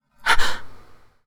freakedbreath.wav